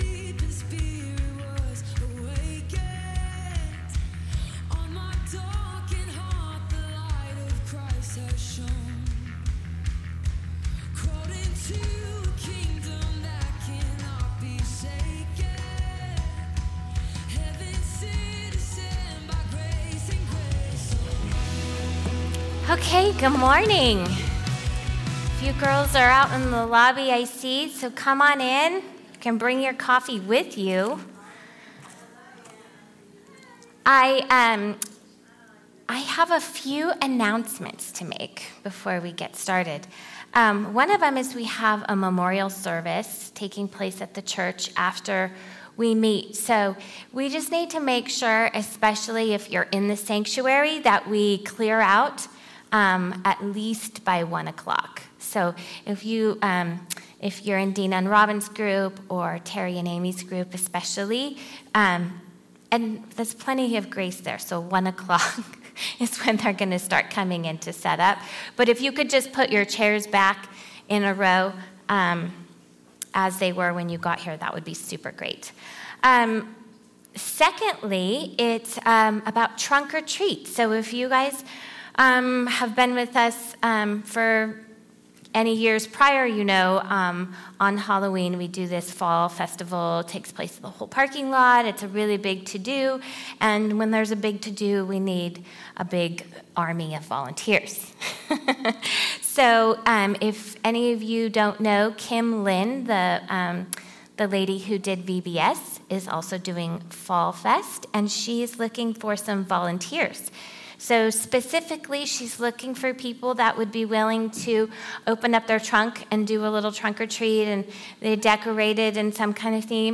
Week 7 Message